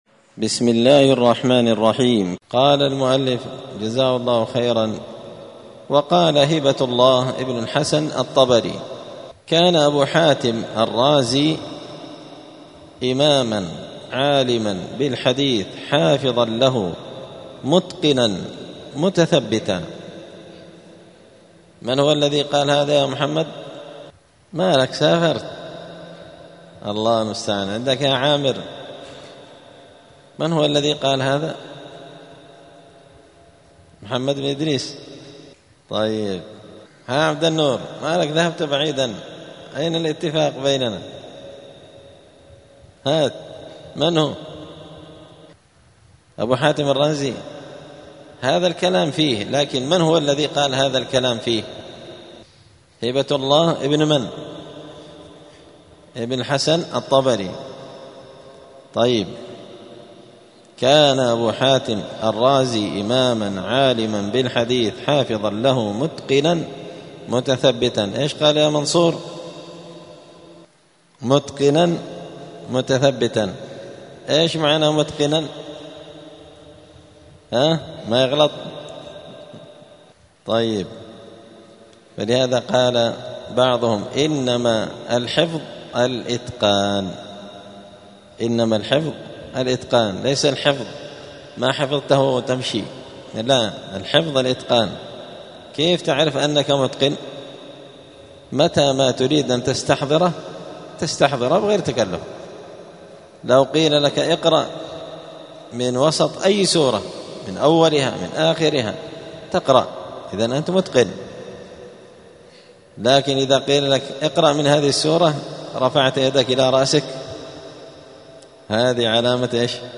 *الدرس التاسع والثمانون (89) باب التعريف با لنقاد أبو حاتم الرازي*